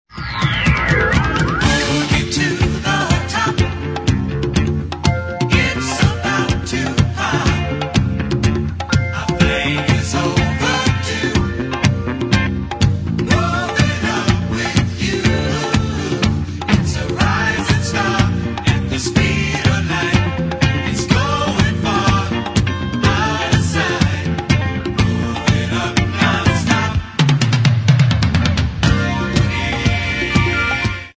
[ DISCO ]